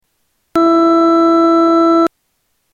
Flutes- Elka Panther 300
Category: Sound FX   Right: Personal